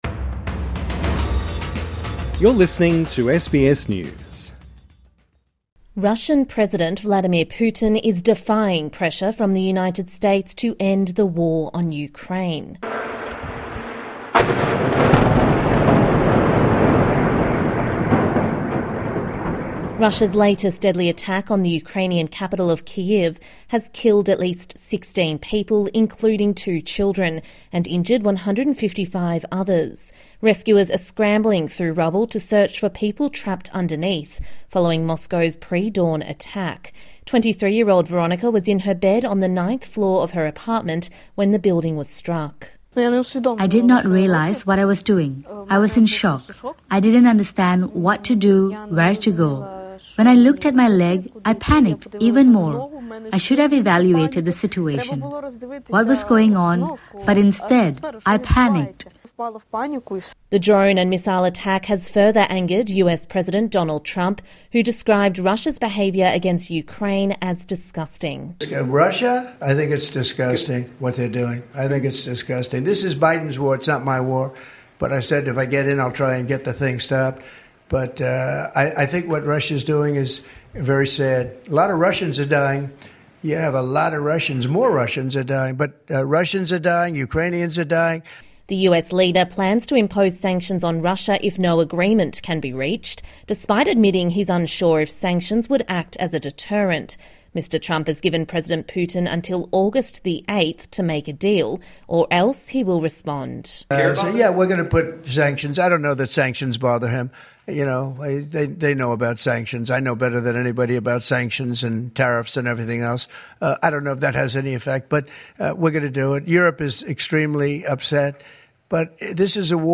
(Sound of explosions in Kyiv) Russia's latest deadly attack on the Ukrainian capital of Kyiv has killed at least 16 people, including two children, and injured 155 others.